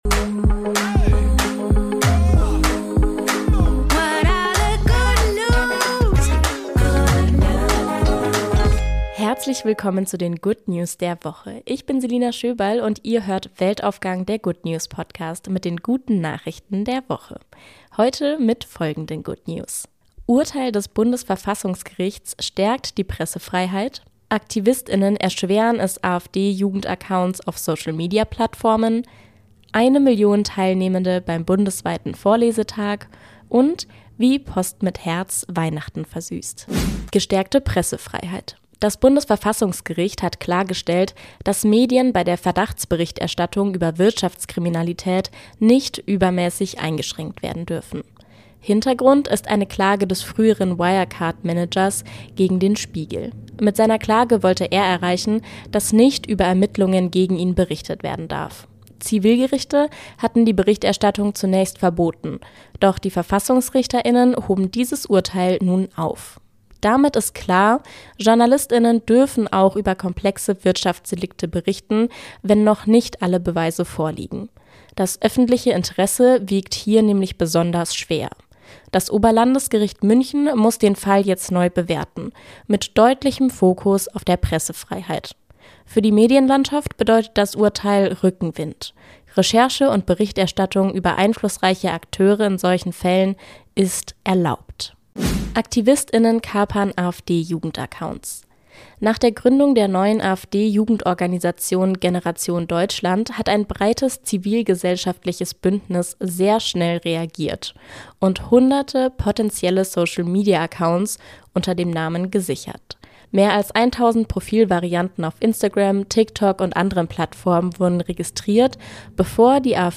Jede Woche wählen wir aktuelle gute Nachrichten aus und tragen sie